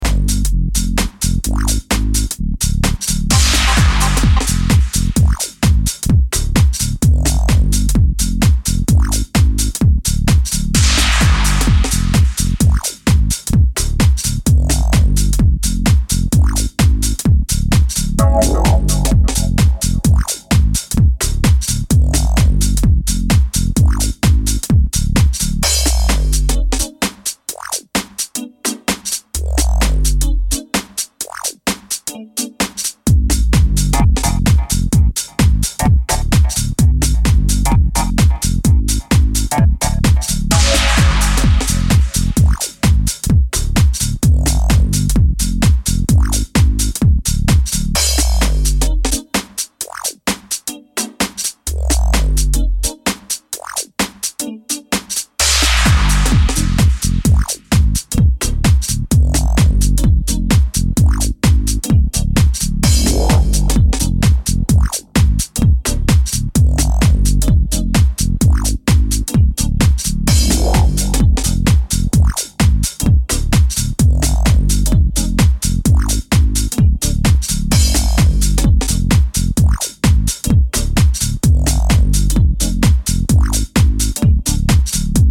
4 dancefloor burners